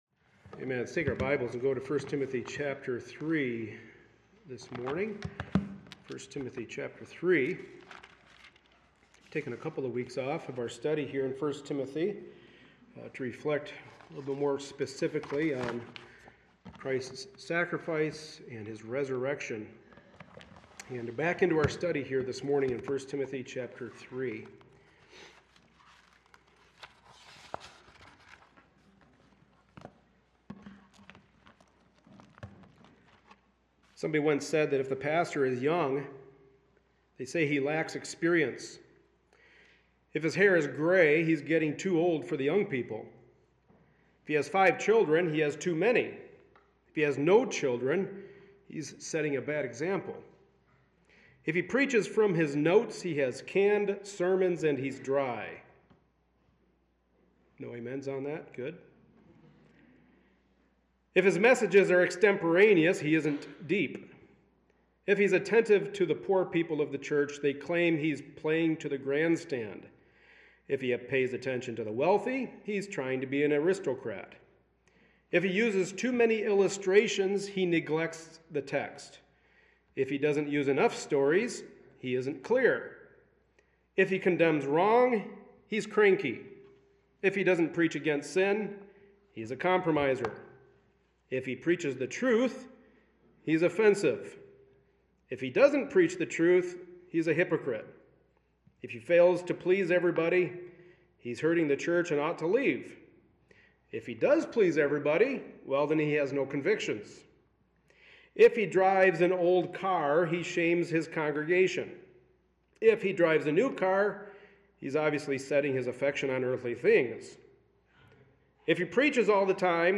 1 Timothy 3:1-7 Service Type: Sunday Morning Service A study in the pastoral epistles.